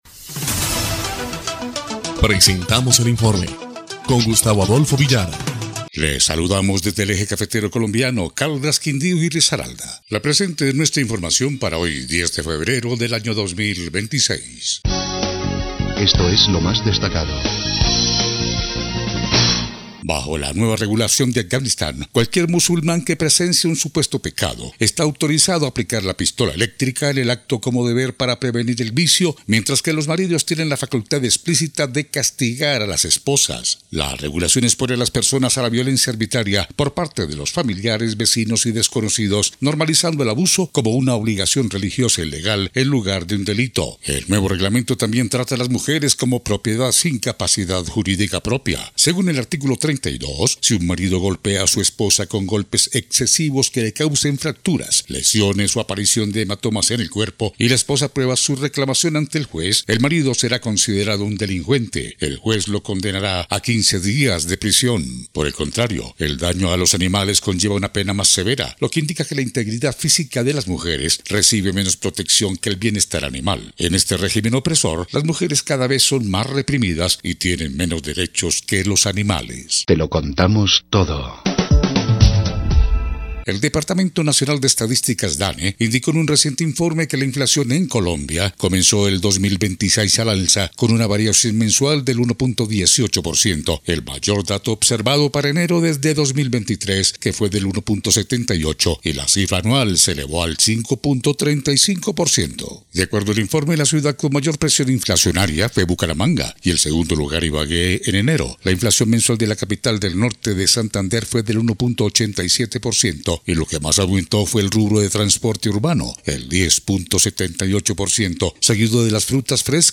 EL INFORME 3° Clip de Noticias del 10 de febrero de 2026